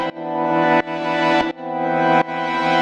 GnS_Pad-MiscA1:2_170-E.wav